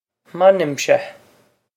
m'ainmse MAN-yim-sheh
This is an approximate phonetic pronunciation of the phrase.